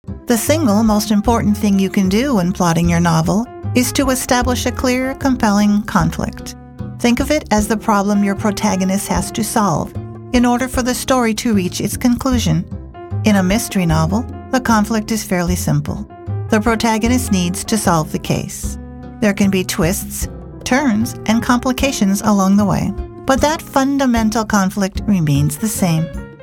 Female
Explainer Videos
Non-Fiction, Explainer
Words that describe my voice are Believable, friendly, approachable.